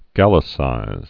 (gălĭ-sīz)